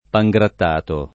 vai all'elenco alfabetico delle voci ingrandisci il carattere 100% rimpicciolisci il carattere stampa invia tramite posta elettronica codividi su Facebook pangrattato [ pa jg ratt # to ] o pan grattato [ id. ] s. m. (gastron.)